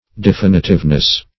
Definitiveness \De*fin"i*tive*ness\, n.
definitiveness.mp3